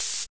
5213R OHH.wav